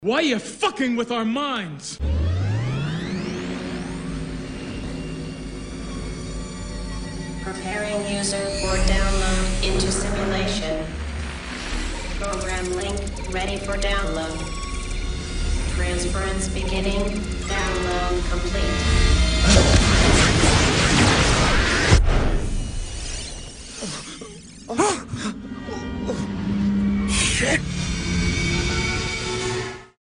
• Качество: 320, Stereo
голосовые
электронные